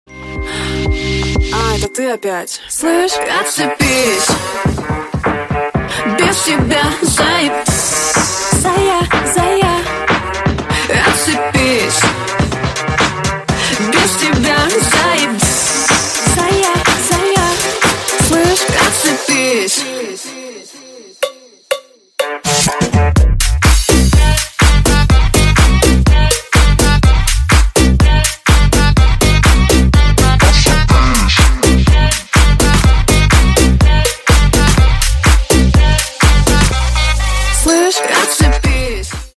Рингтоны Ремиксы » # Танцевальные Рингтоны